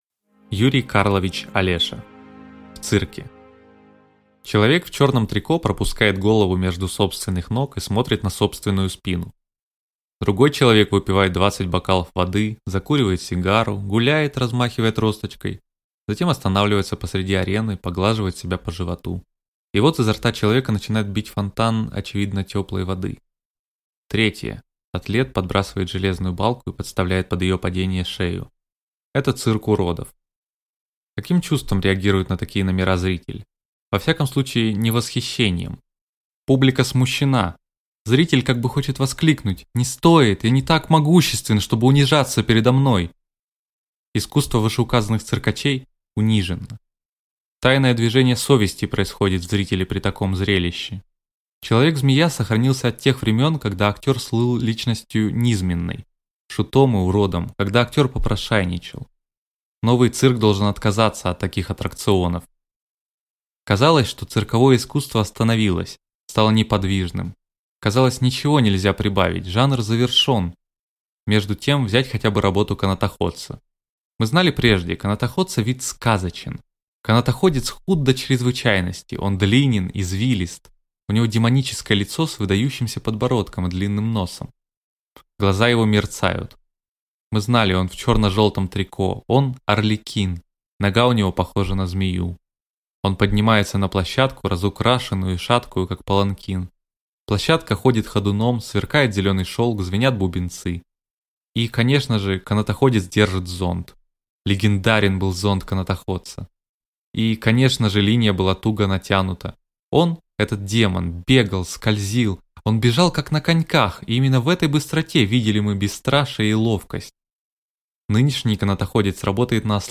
Аудиокнига В цирке | Библиотека аудиокниг